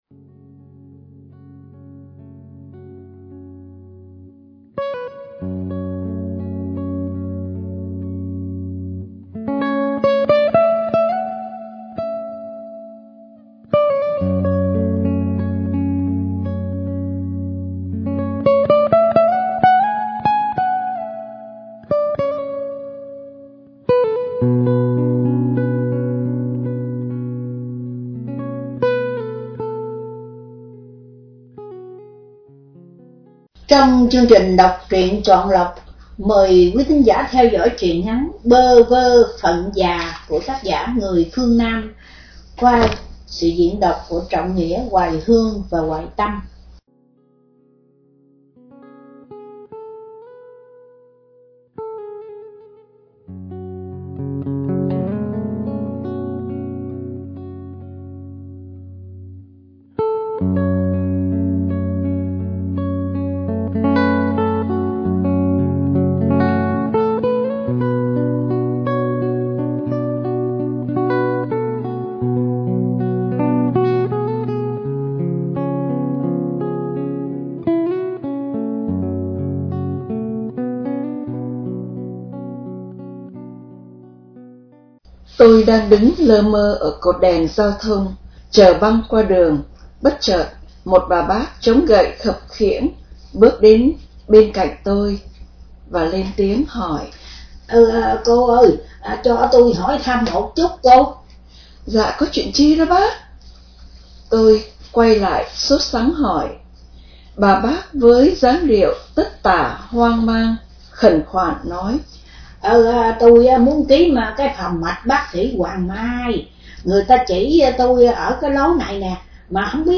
Đọc Truyện